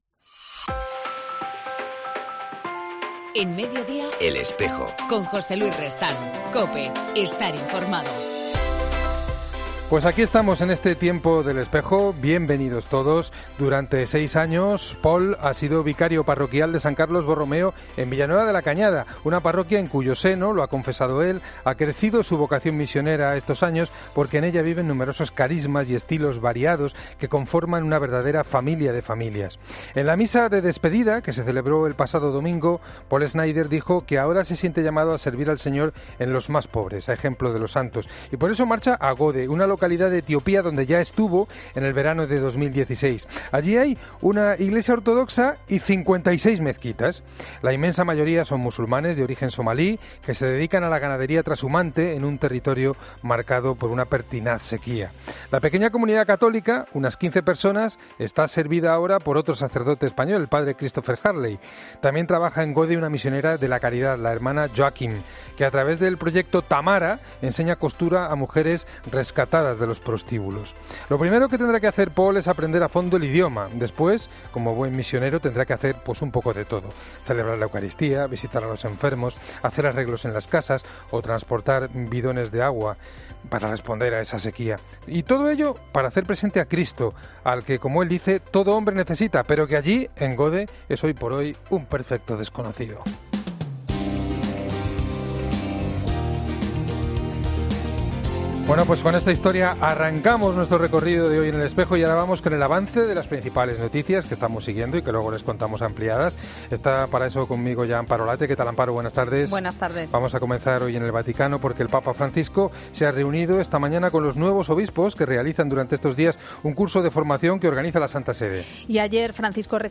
En El Espejo del 14 de septiembre hablamos con el obispo de la Diócesis de Cádiz-Ceuta, Rafael Zornoza